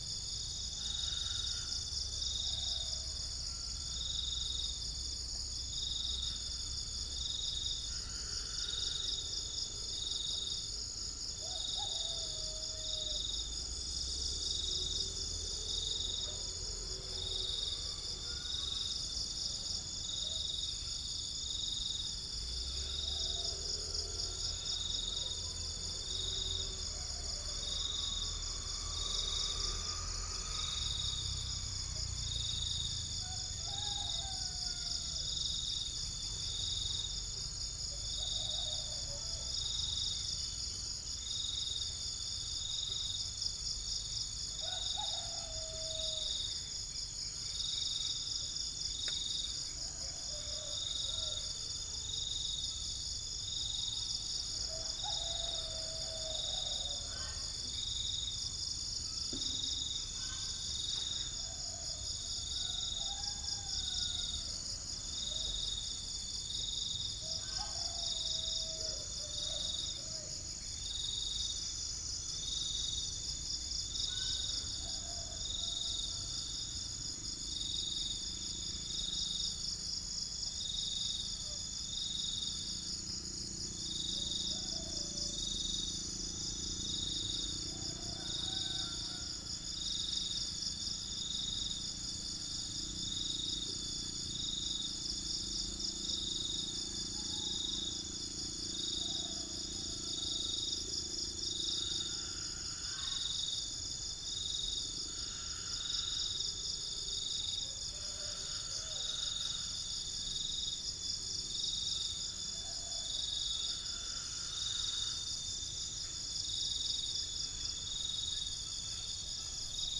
39864 | Gallus 39868 | 0 - unknown bird 39865 | Pycnonotus 39867 | Dicaeum trigonostigma